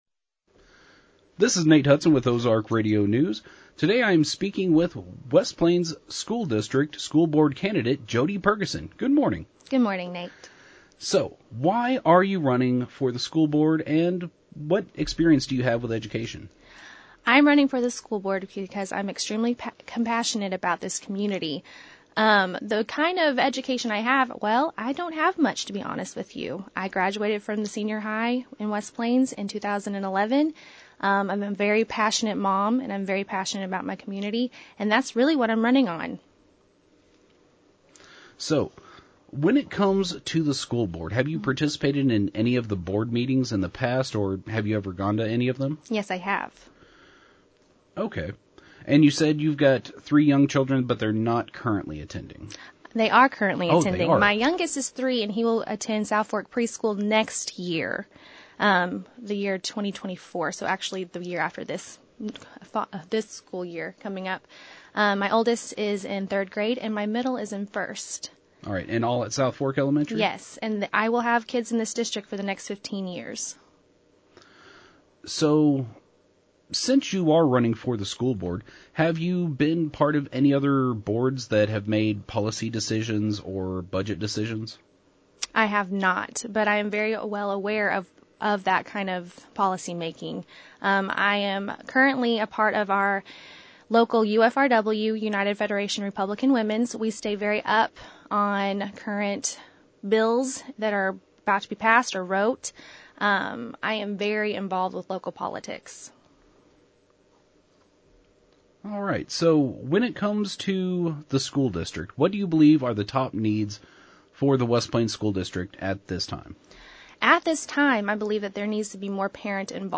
Our first interview is with challenger